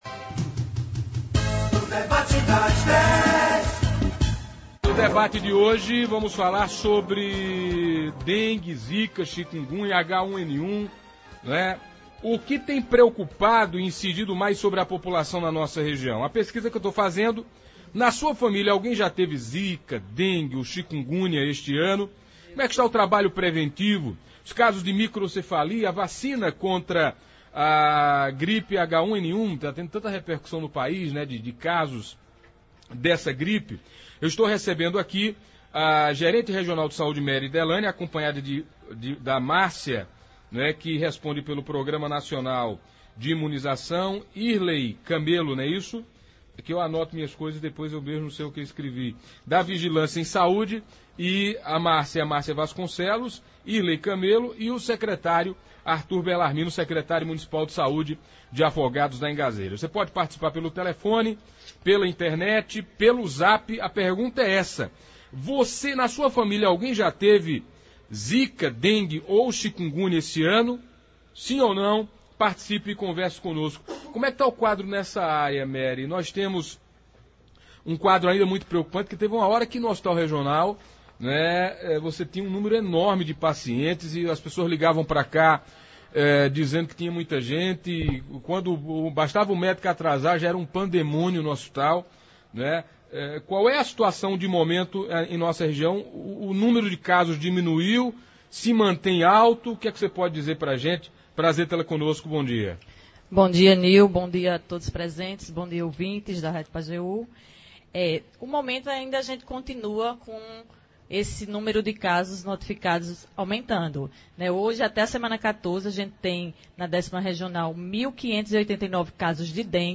No Debate das Dez desta quinta-feira (14) na Rádio Pajeú